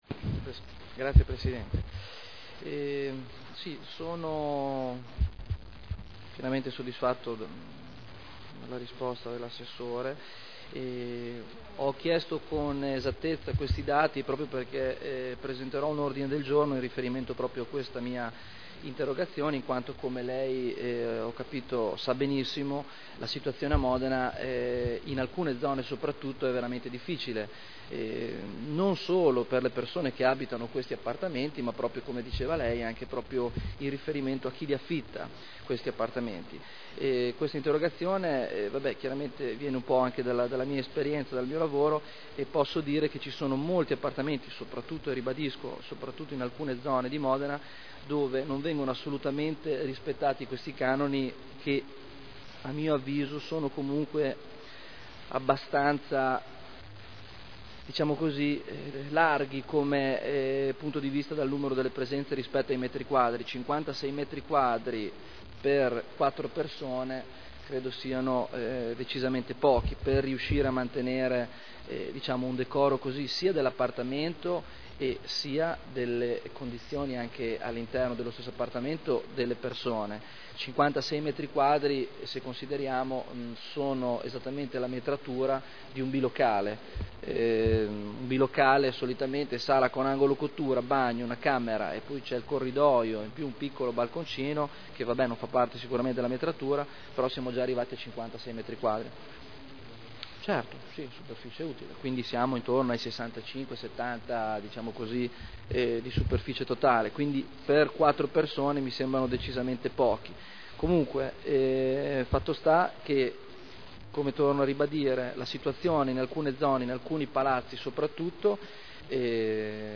Stefano Barberini — Sito Audio Consiglio Comunale
Interrogazione del consigliere Barberini (Lega Nord) avente per oggetto: “Appartamenti affollati” (presentata l’8 gennaio 2010 – in trattazione il 15.2.2010)